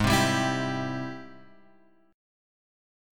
G# Augmented Major 7th